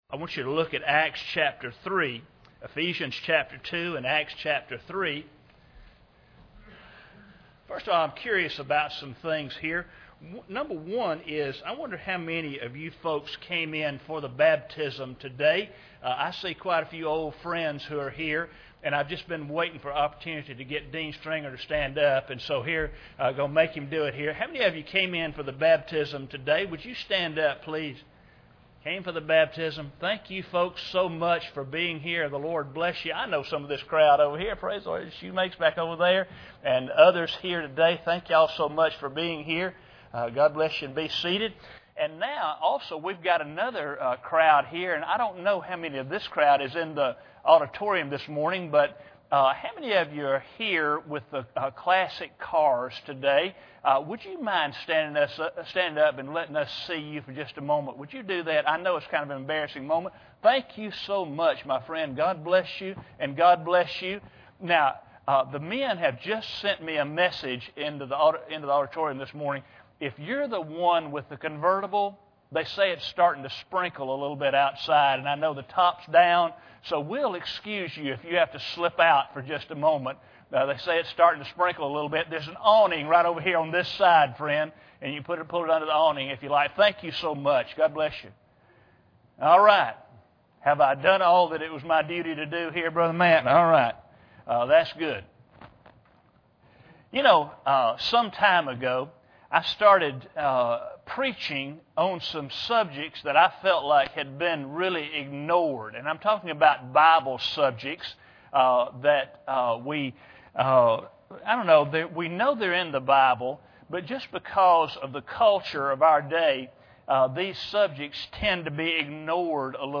Acts 3:19 Service Type: Sunday Morning Bible Text